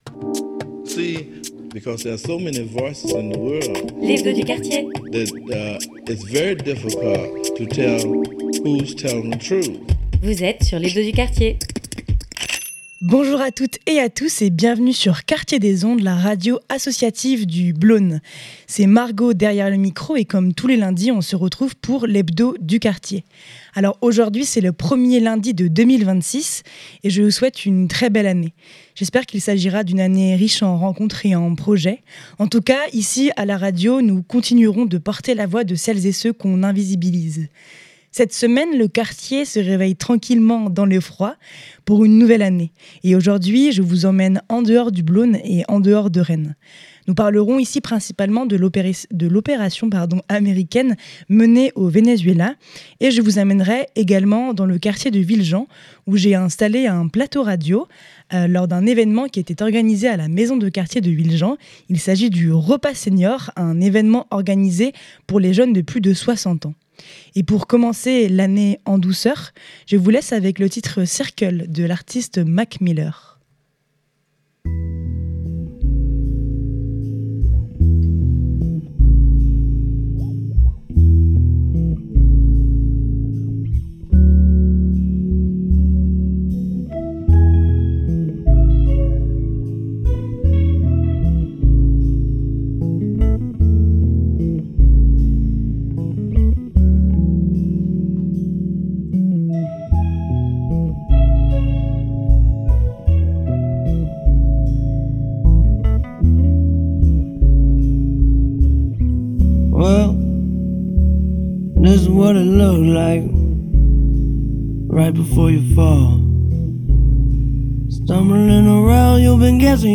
Nous parlerons ici principalement de l’opération américaine menée au Venezuela et je vous amènerais également dans le quartier de Villejean, où j’ai installé un plateau radio lors d’un repas de Noël organisé à la Maison de Quartier de Villejean pour des jeunes de plus de 60 ans.